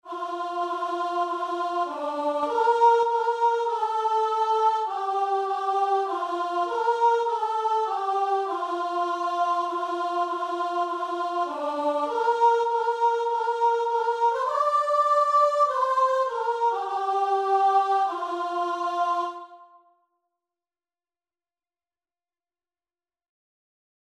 Christian
4/4 (View more 4/4 Music)
Guitar and Vocal  (View more Easy Guitar and Vocal Music)